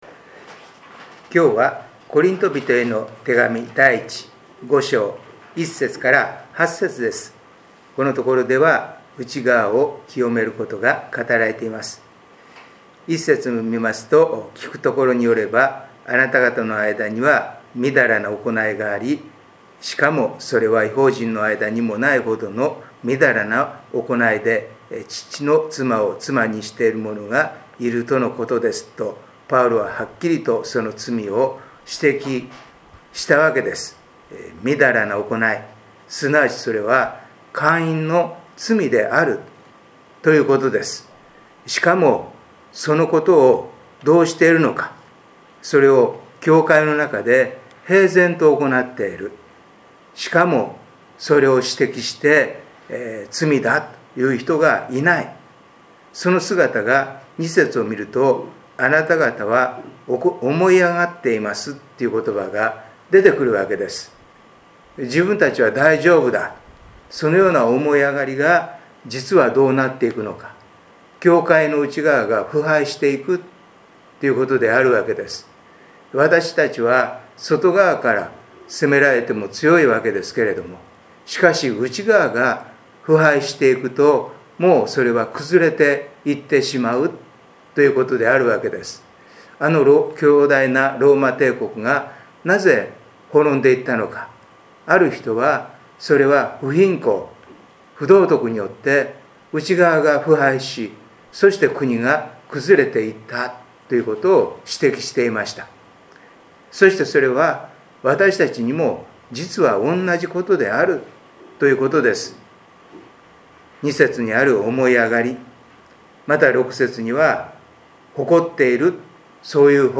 礼拝メッセージ「神の御力」│日本イエス・キリスト教団 柏 原 教 会